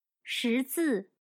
识字/Shízì/Alfabetizarse.